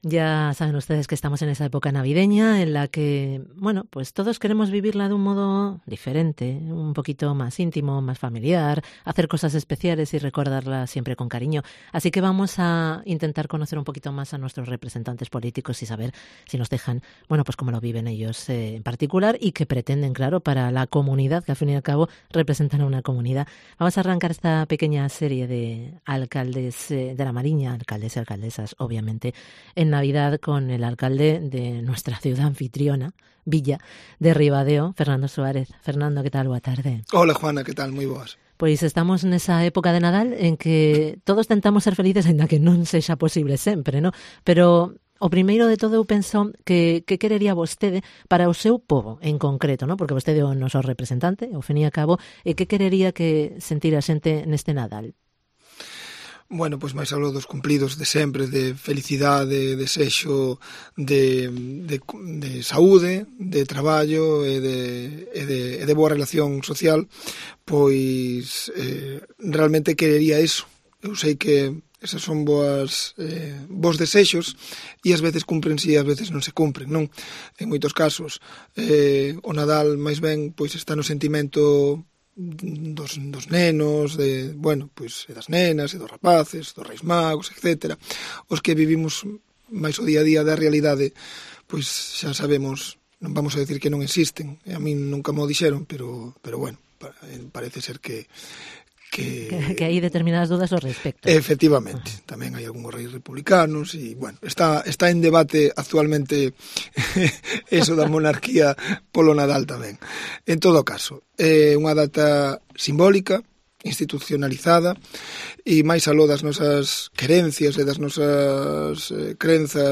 ENTREVISTA con Fernando Suárez, alcalde de Ribadeo